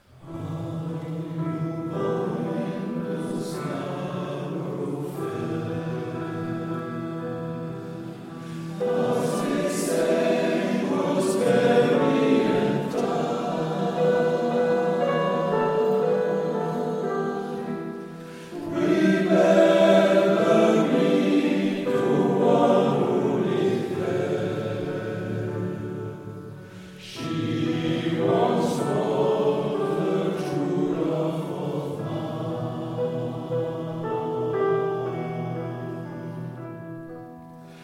Chant traditionnel médiéval du 15è siècle